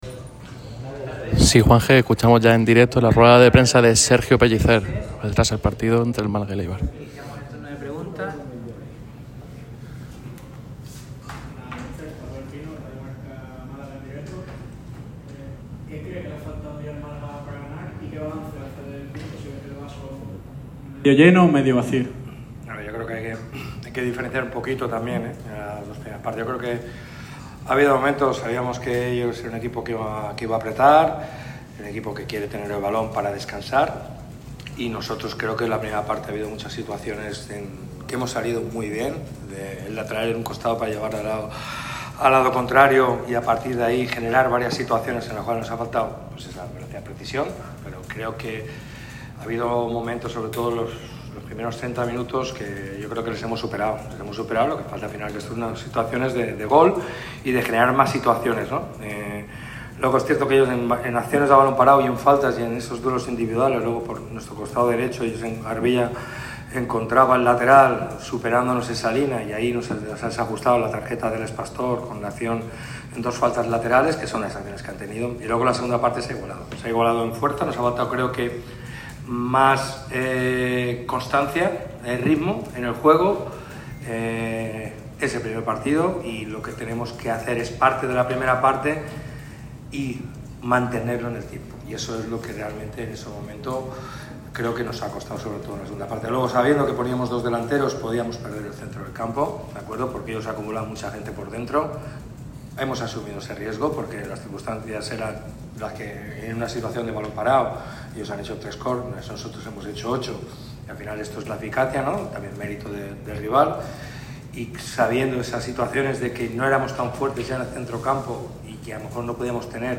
Sergio Pellicer ha pasado por sala de prensa después del empate del Málaga CF ante el Eibar. El técnico malaguista mostró una gran preocupación por la lesión de Luismi.